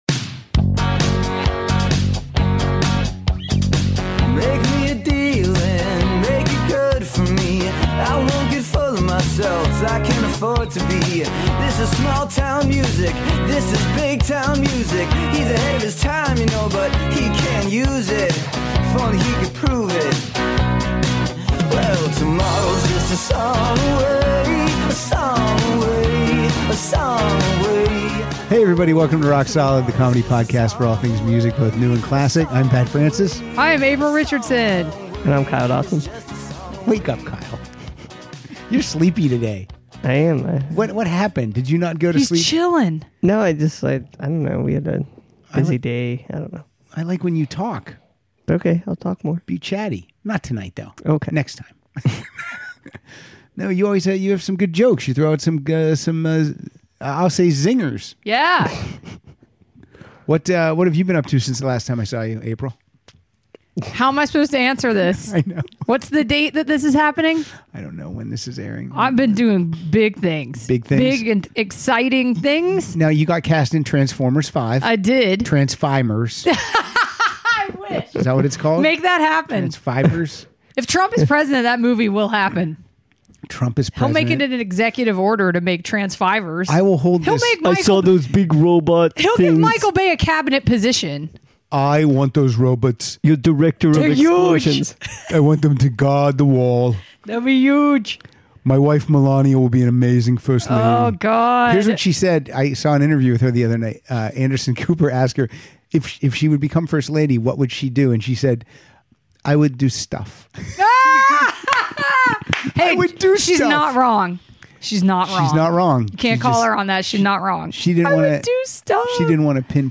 play their favorite songs with the words "want" or "need" in the title.